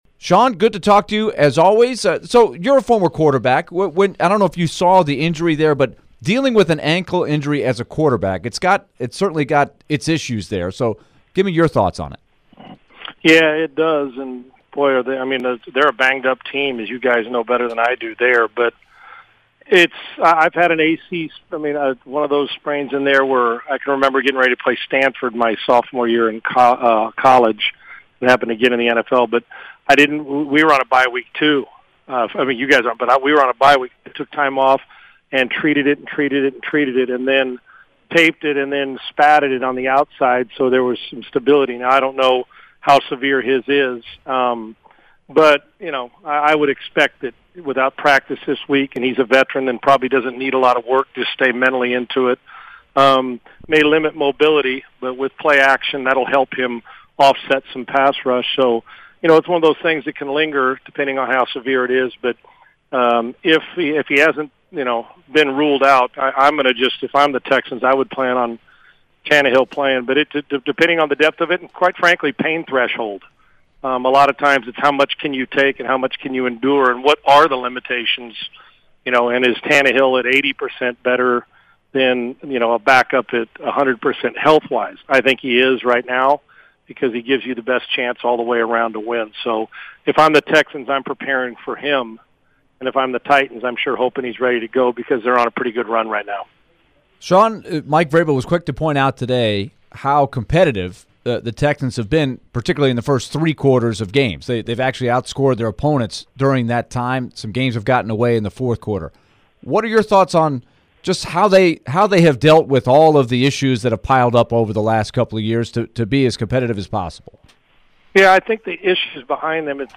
Sean Salisbury interview (10-26-22)